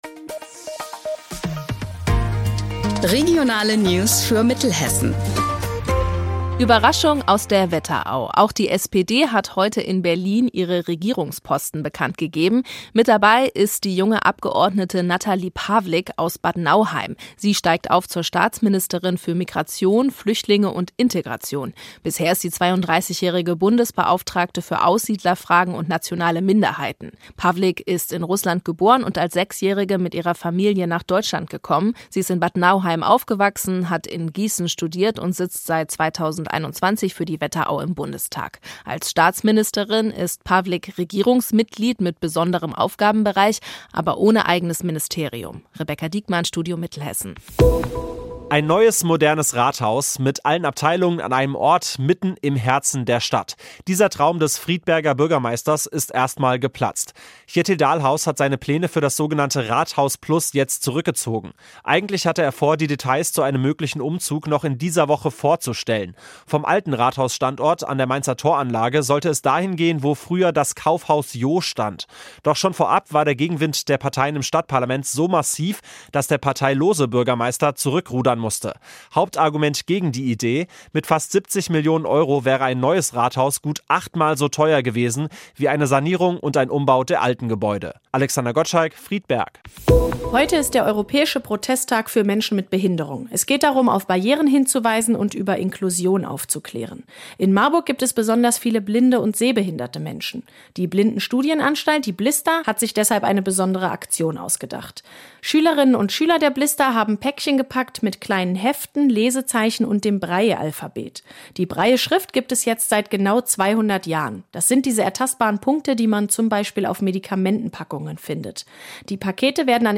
Mittags eine aktuelle Reportage des Studios Gießen für die Region